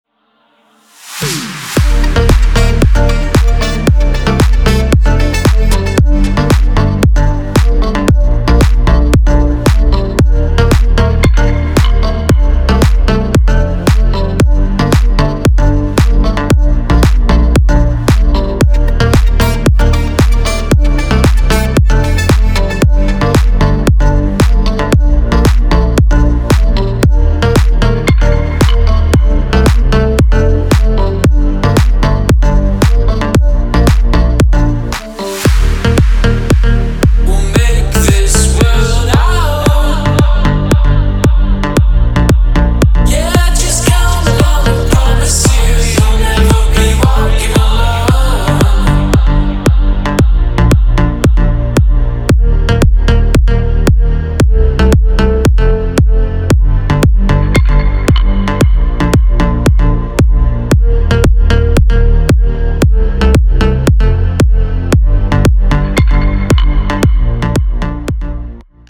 • Качество: 320, Stereo
deep house
dance
club
спокойная мелодия